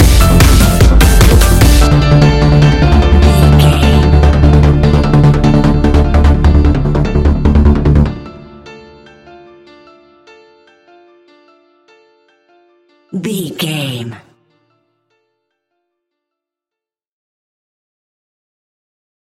Aeolian/Minor
D
Fast
heavy
energetic
uplifting
hypnotic
drum machine
piano
synthesiser
acid house
synth leads
synth bass